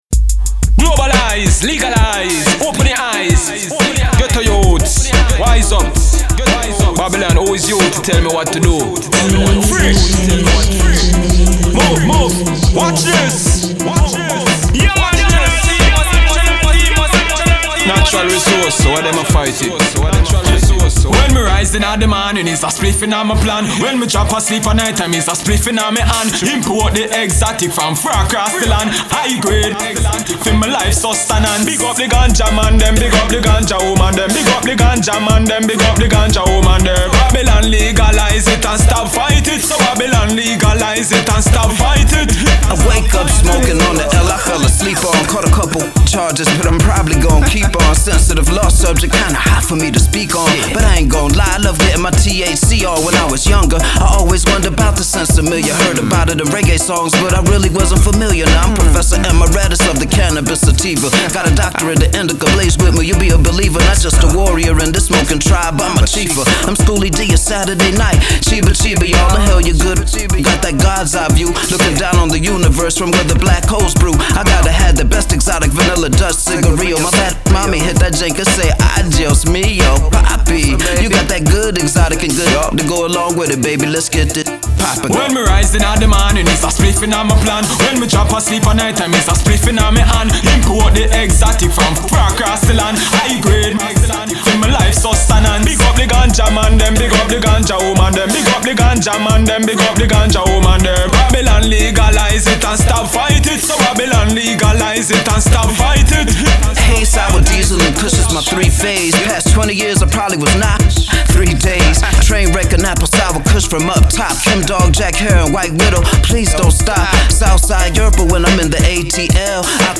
heavily Reggae flavored Hip Hop track